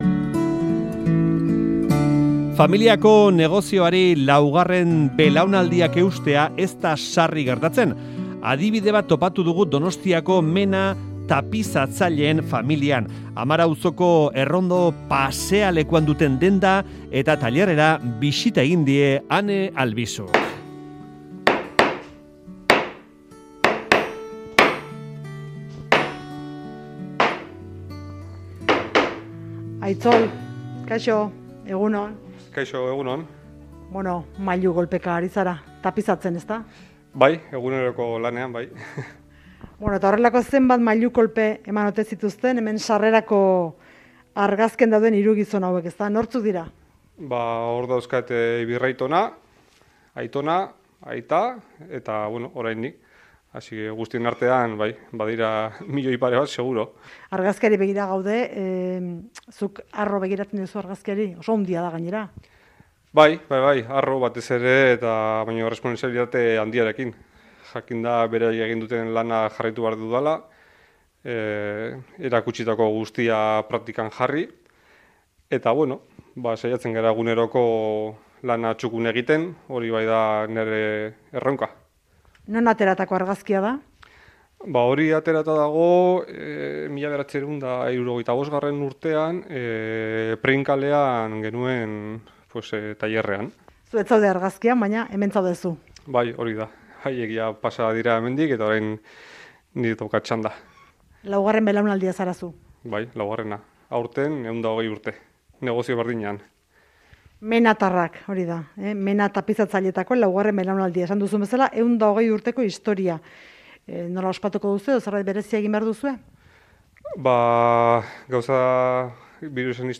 Tapizatzerakoan nola lan egiten duten erakutsi digu tailerrean.